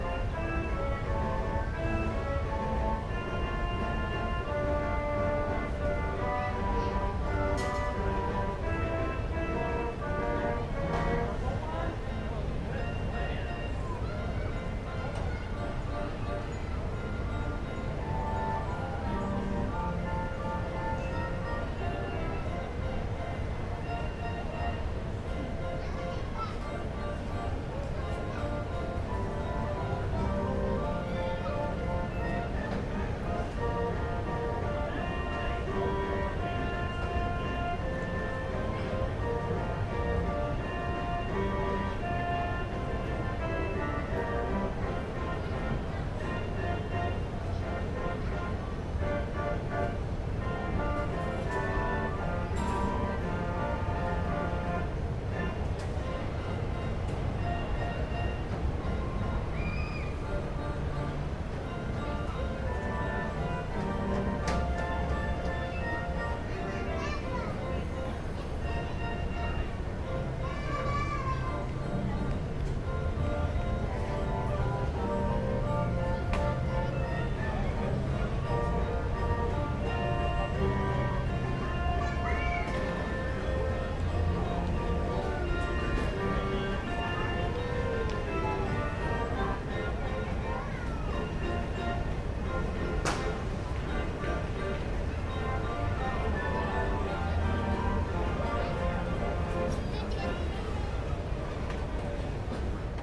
funfair.mp3